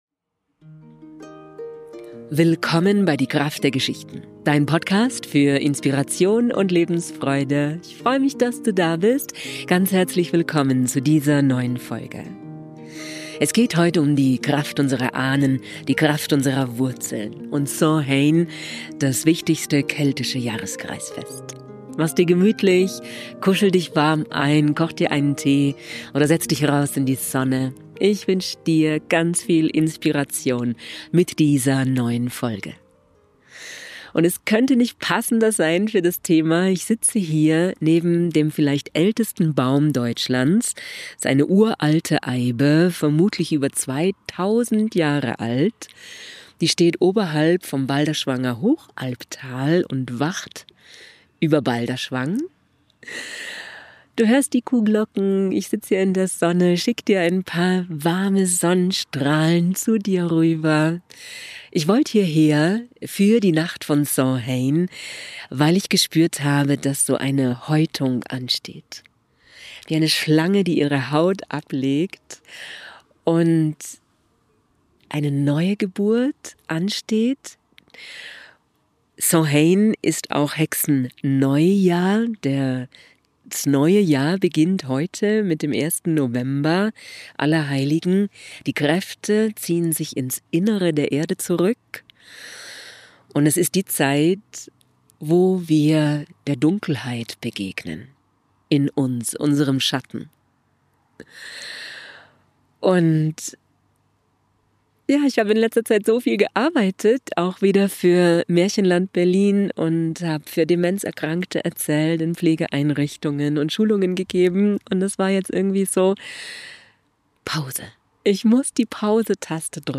Ich war in Balderschwang bei der 2000jährigen Eibe und habe diese Folge für Dich aufgenommen.